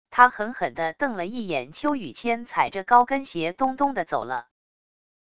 她狠狠地瞪了一眼秋语千踩着高跟鞋咚咚地走了wav音频生成系统WAV Audio Player